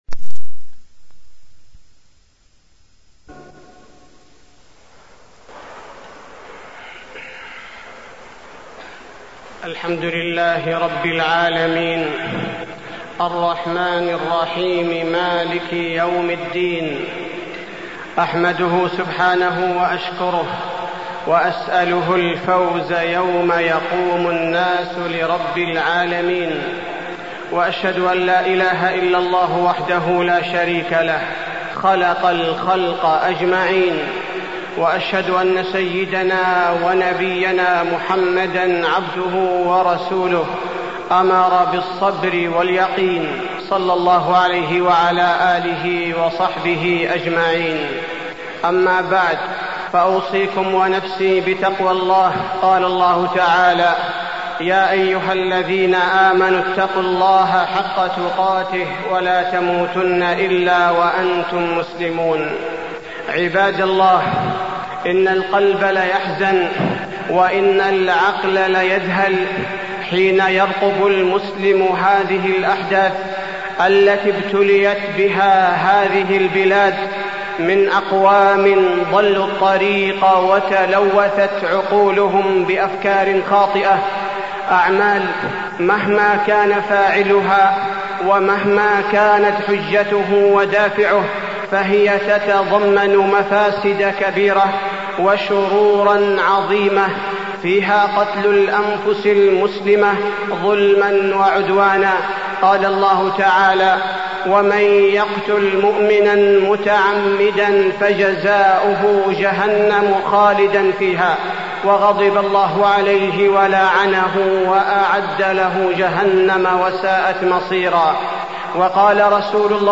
تاريخ النشر ١١ ربيع الأول ١٤٢٥ هـ المكان: المسجد النبوي الشيخ: فضيلة الشيخ عبدالباري الثبيتي فضيلة الشيخ عبدالباري الثبيتي الأحداث